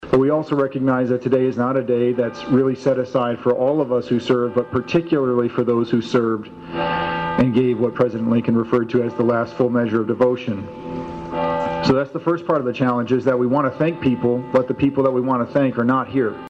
Coldwater’s Memorial Day ceremony was held in the newly renovated Four Corners Park in front of a large gathering on a sun splashed morning.